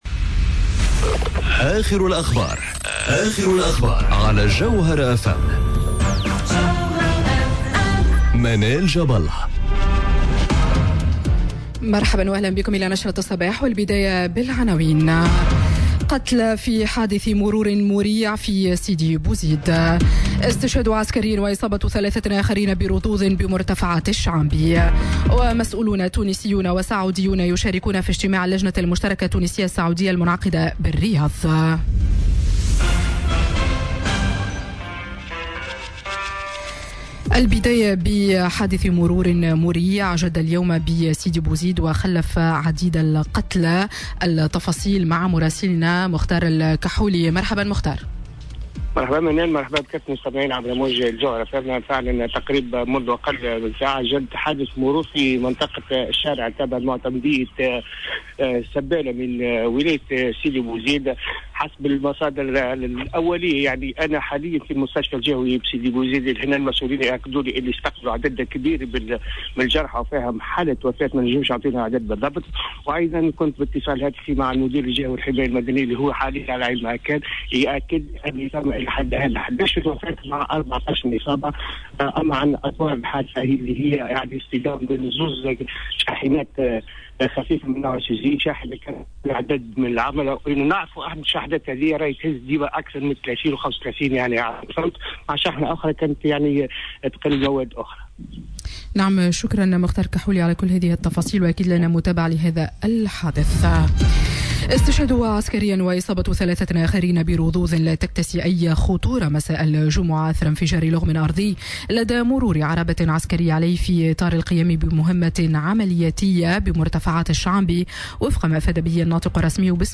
نشرة أخبار السابعة صباحا ليوم الخميس 25 أفريل 2019